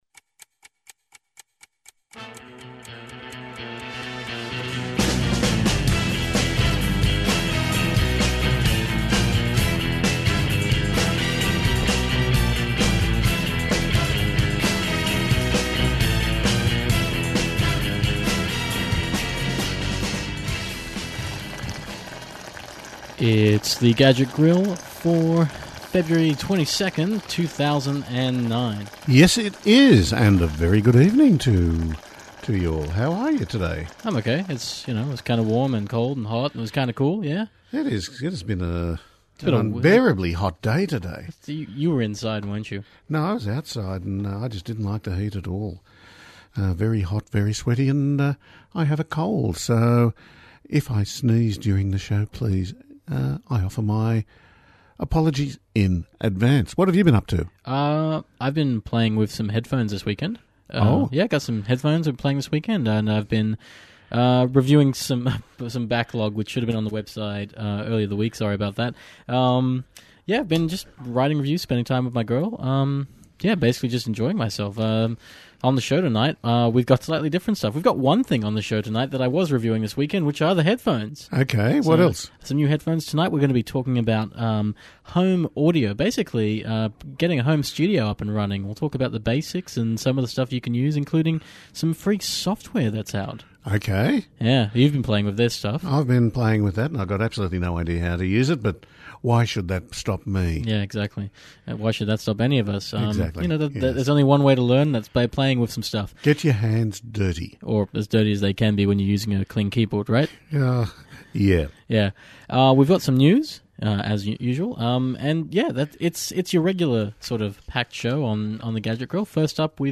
We’re taking Plantronics’ Gamecom 367 and 377 headsets for a spin. We’ll tell you what we think live on the air.